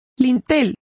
Complete with pronunciation of the translation of lintel.